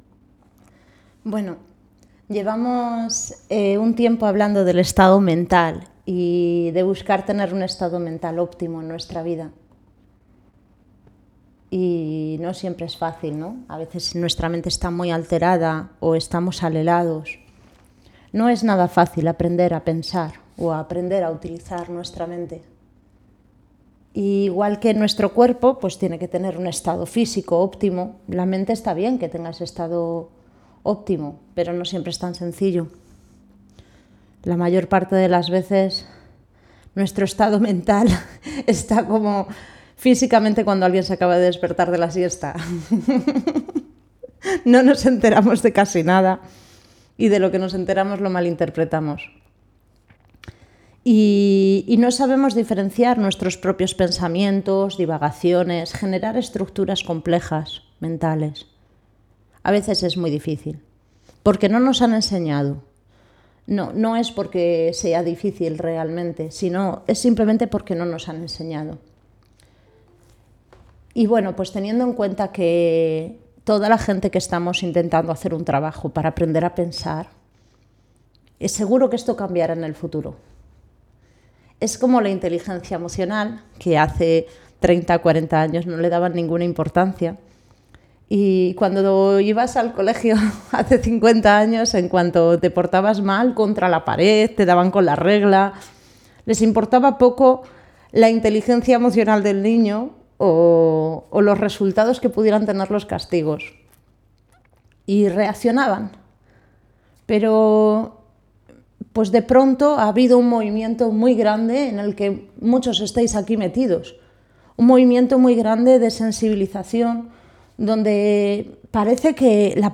Charla: Estado mental luminoso y de apertura ante la adversidad.